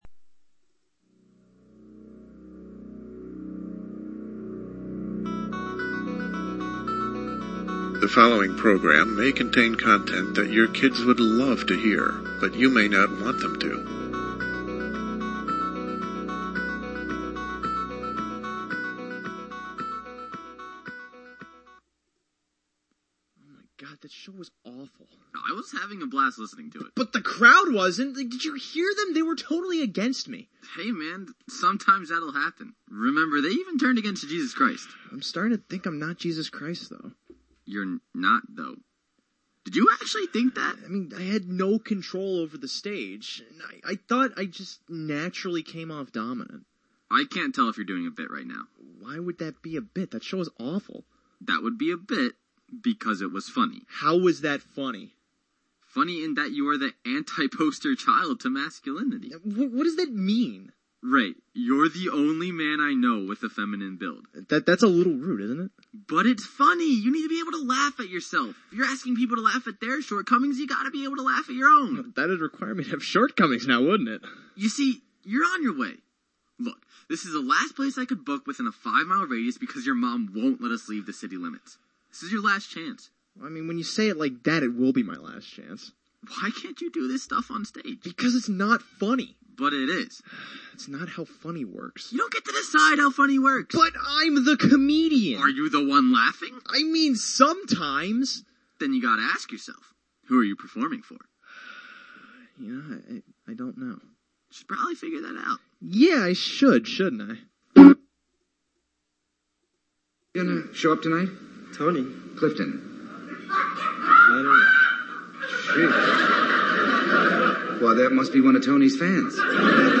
broadcast
comedy